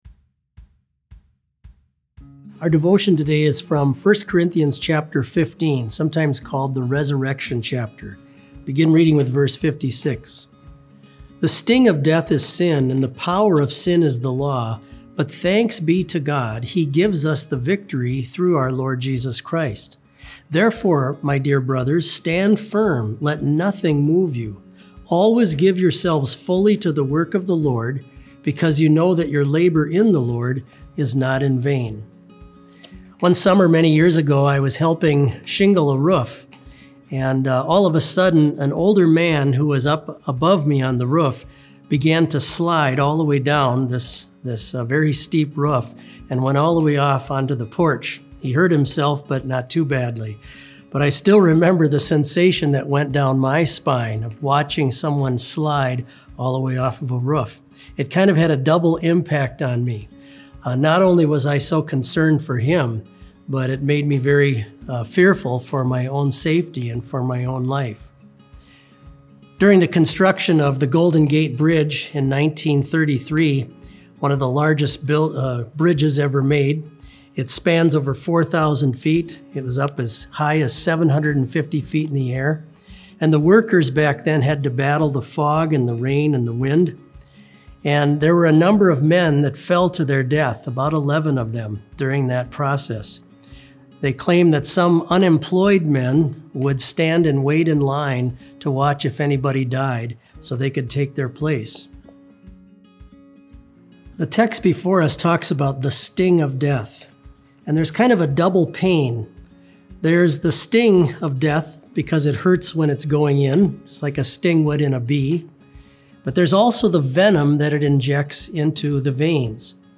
Complete service audio for BLC Devotion - April 14, 2020